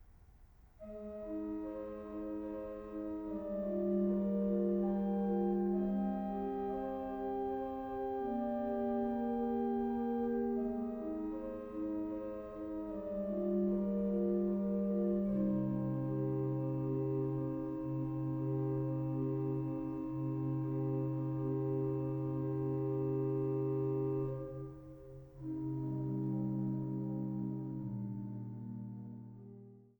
Stellwagen-Orgel